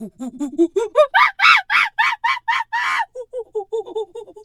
monkey_2_chatter_scream_08.wav